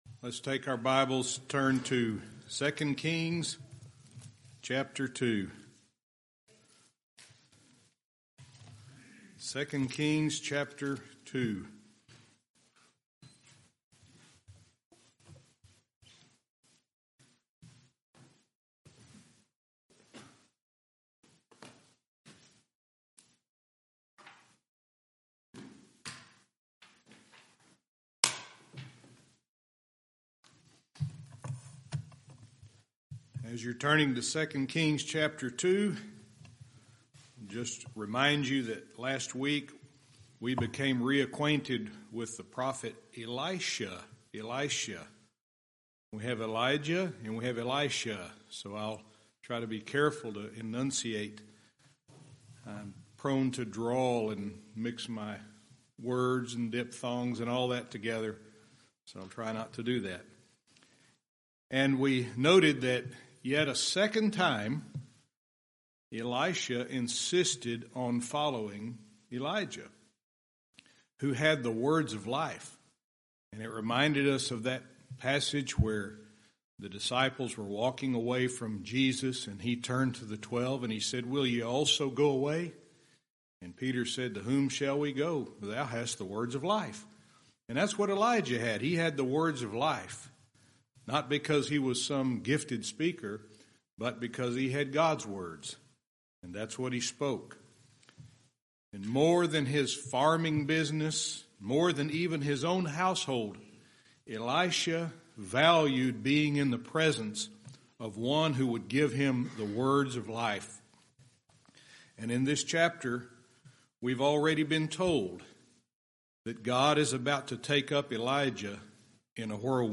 Verse by verse teaching - 2 Kings 2:3-11